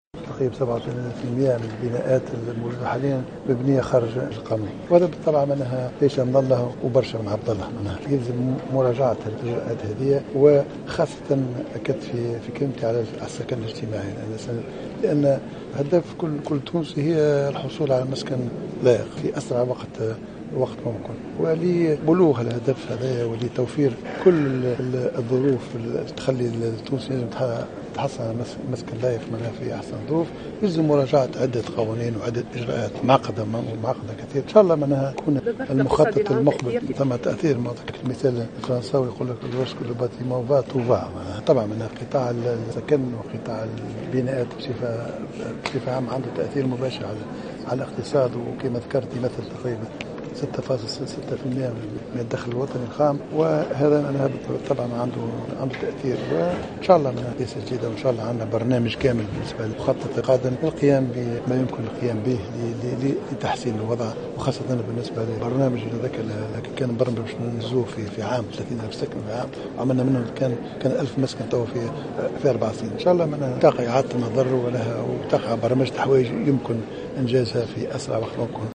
وقال الصيد في تصريح لمراسل الجوهرة أف أم إنه يجب مراجعة الإجراءات المتعلقة بالسكن الاجتماعي مضيفا أن الوزارة ستعد في المخطط القادم برنامجا لتحسين وضع المساكن وستعمل على توفير الظروف الملائمة ليتحصل المواطن على مسكن.